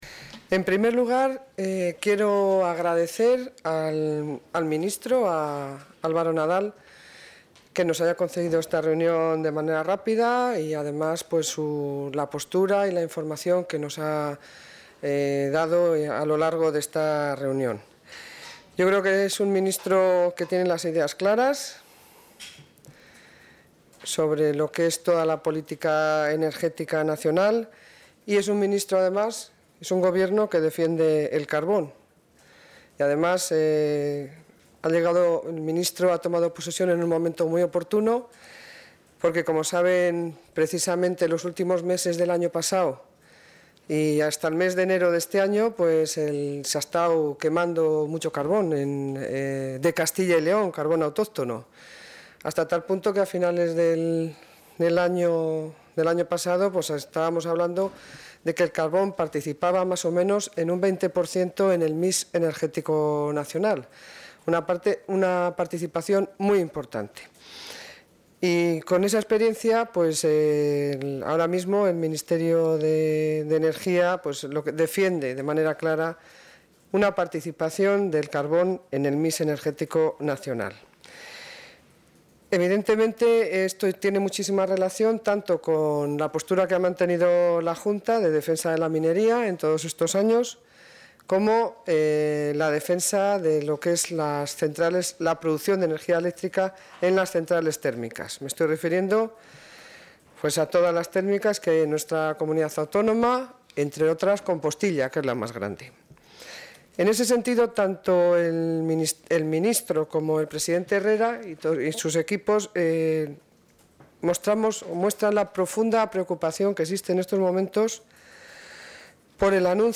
Audio consejera de Economía y Hacienda.
Posteriormente, la consejera de Economía y Hacienda, Pilar del Olmo, ha comparecido ante los medios de comunicación para valorar el encuentro.